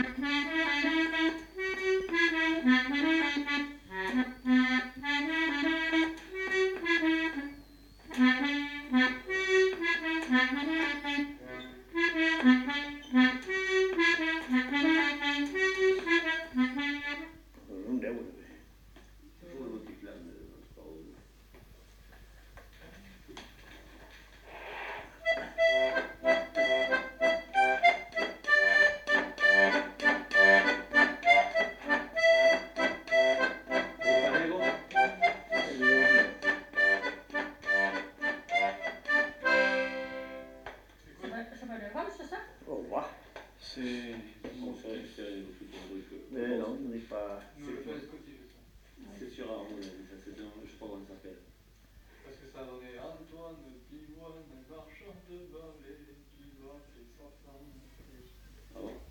Aire culturelle : Petites-Landes
Lieu : Lencouacq
Genre : morceau instrumental
Instrument de musique : accordéon diatonique
Danse : rondeau